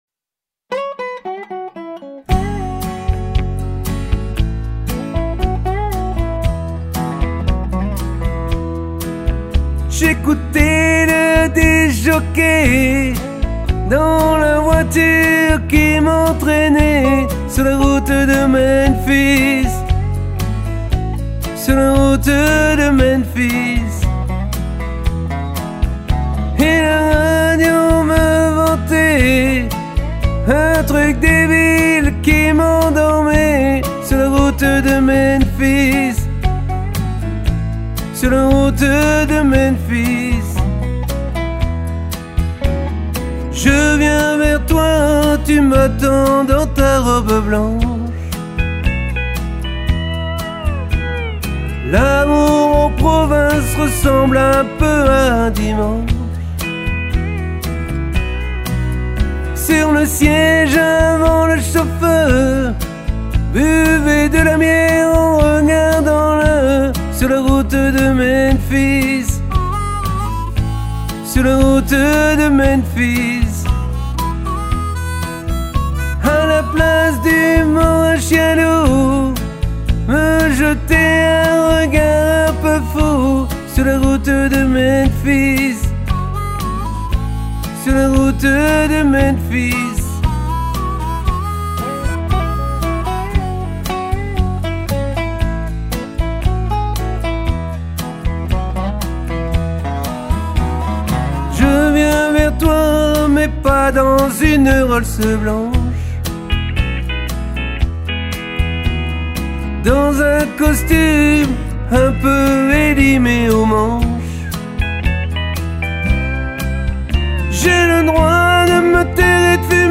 Alto